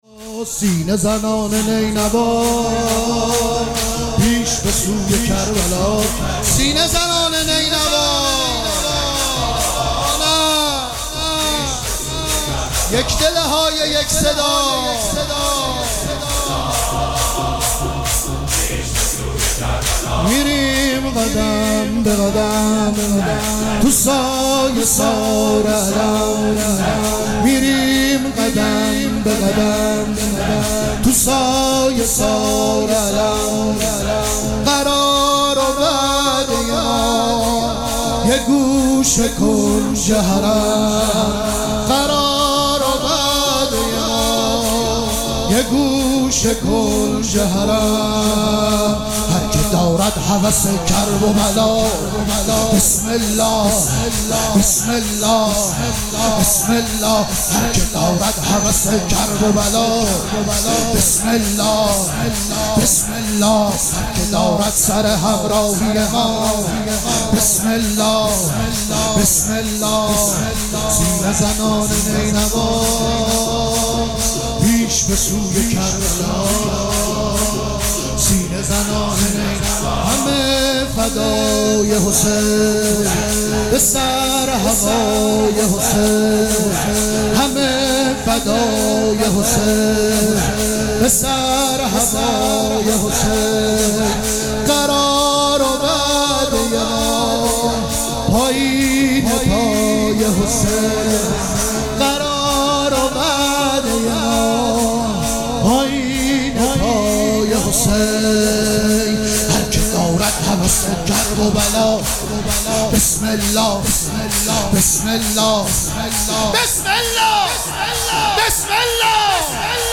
مراسم عزاداری شب هشتم محرم الحرام ۱۴۴۷
شور